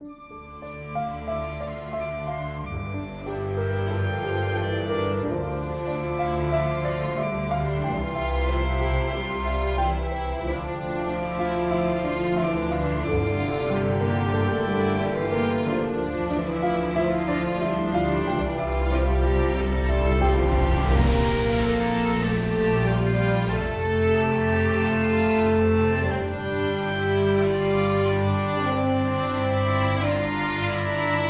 Snare may be sounding a bit ‘thin’, not sure.
Vocoder, was paramount for the genere.